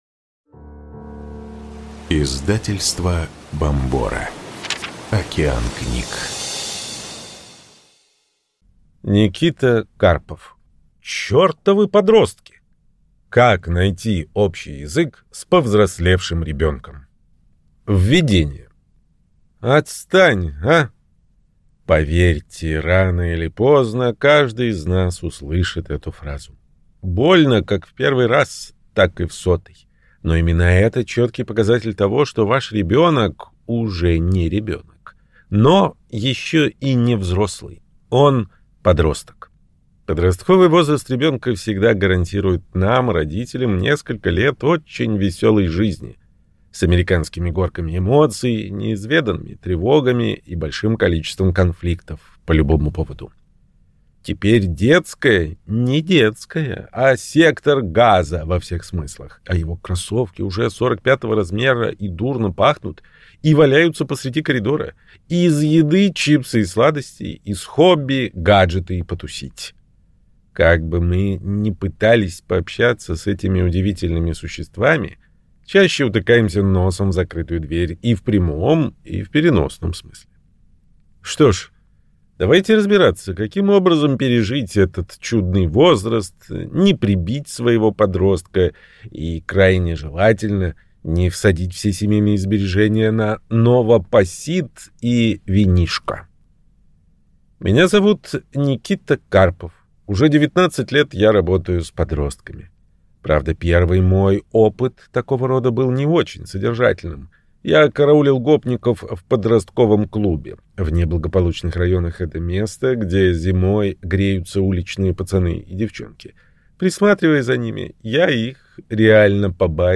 Аудиокнига Чертовы подростки! Как найти общий язык с повзрослевшим ребенком | Библиотека аудиокниг